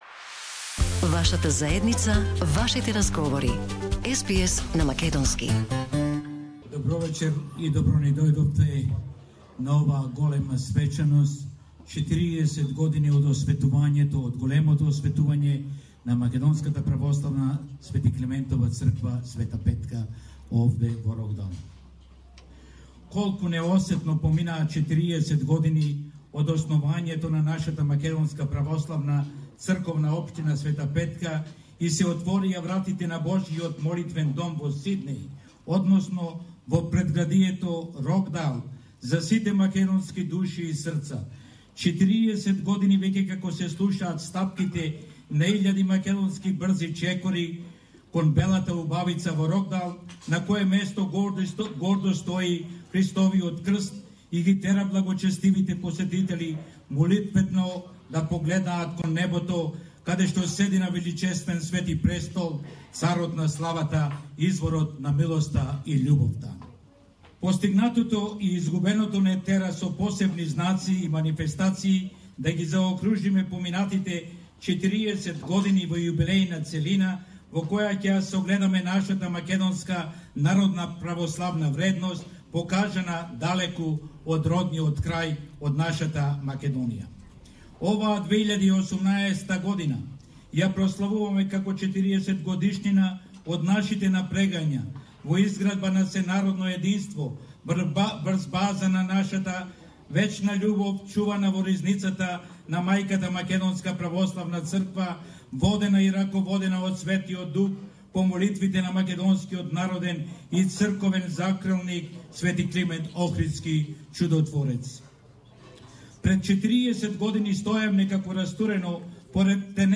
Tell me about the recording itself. Celebration marking the 40th anniversary of MOC "St. Petka" in Sydney Source: SBS